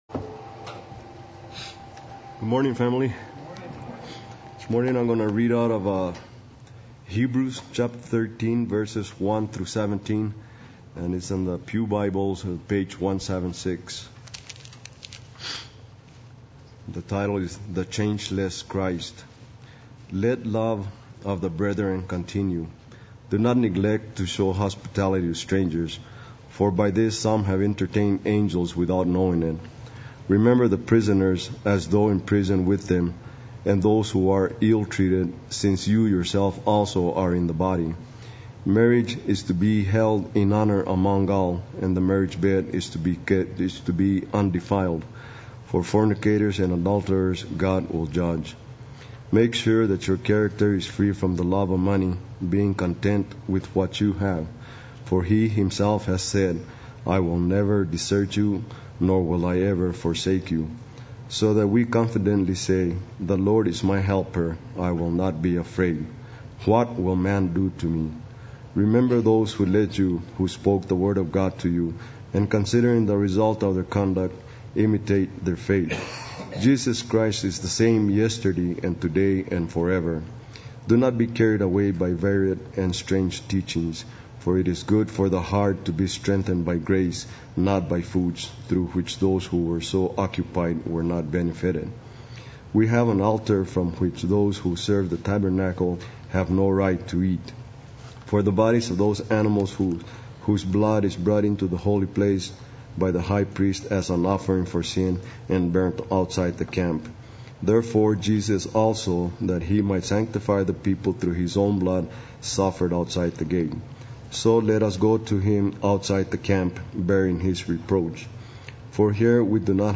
Play Sermon Get HCF Teaching Automatically.
Weeds Among the Wheat Sunday Worship